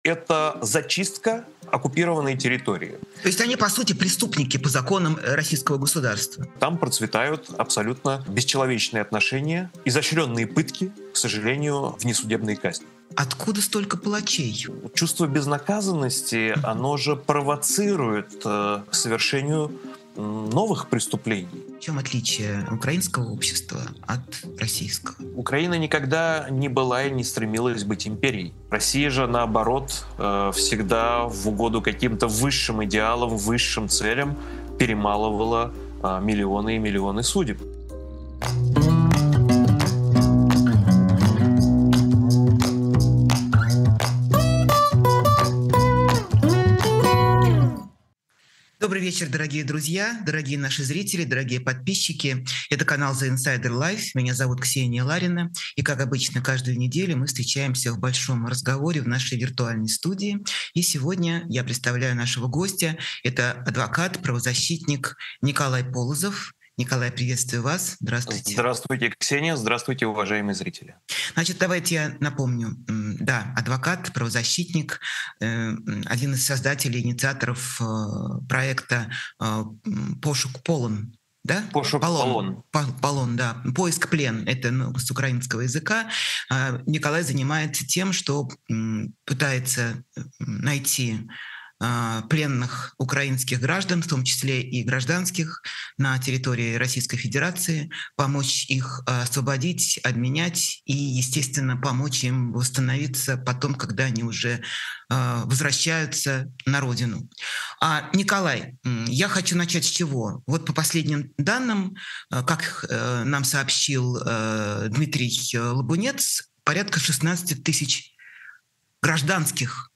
Эфир ведёт Ксения Ларина
Разговор об украинских заложниках путинского режима